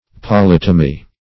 Polytomy \Po*lyt"o*my\